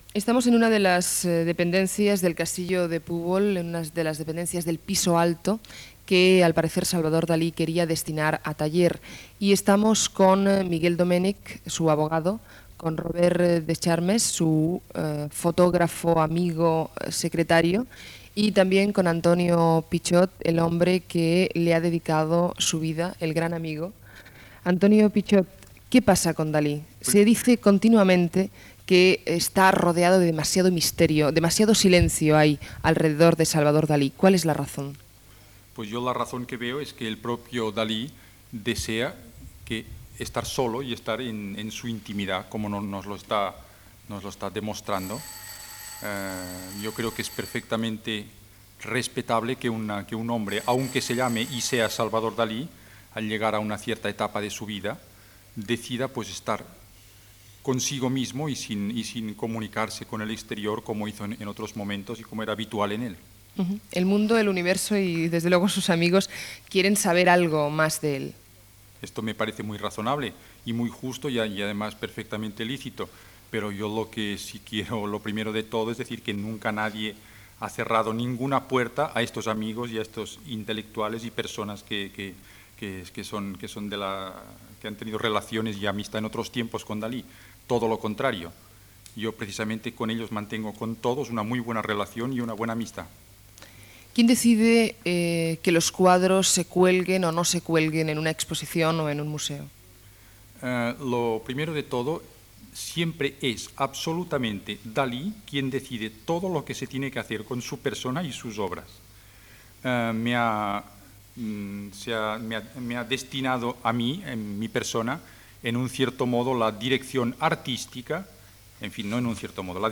Entrevista feta al castell de Púbol